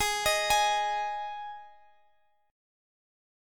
Ab5 chord